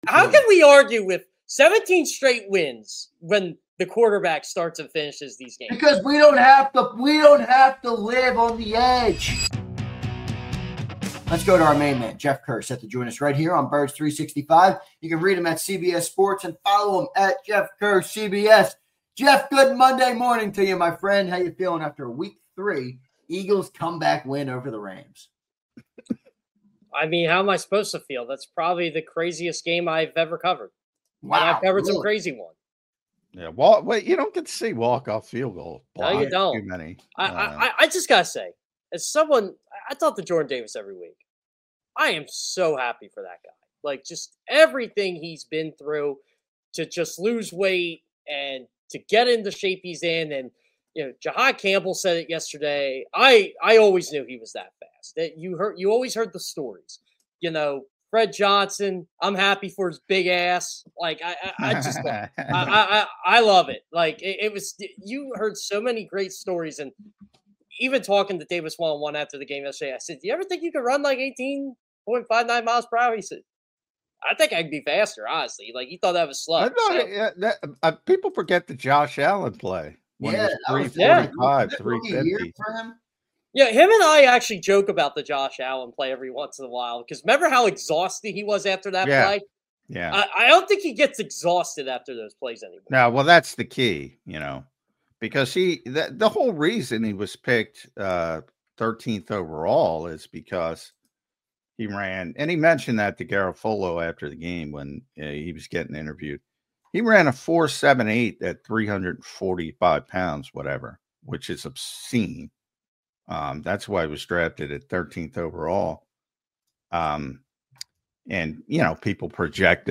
The guys react & break down all the Eagles training camp stories!